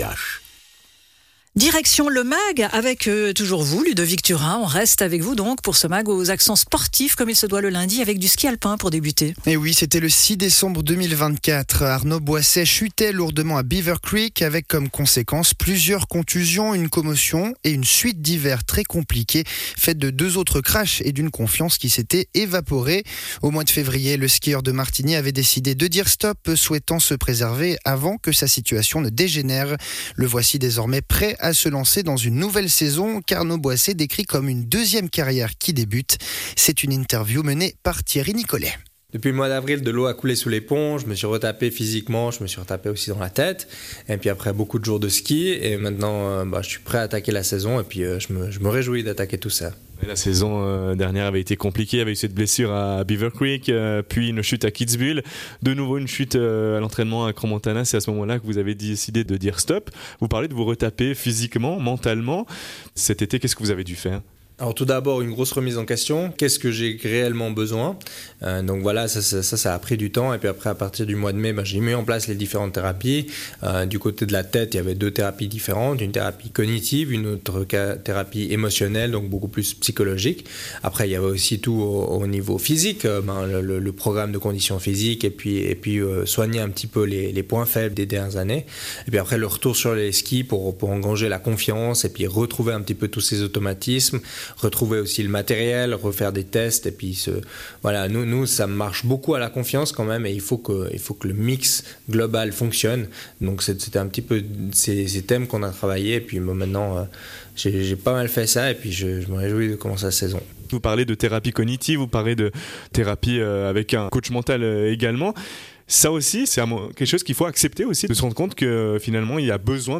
Intervenant(e) : Arnaud Boisset, skieur alpin professionnel